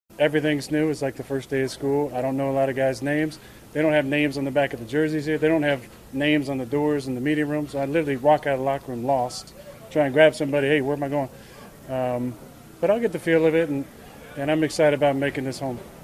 Rodgers says he’s just trying to find his way around right now.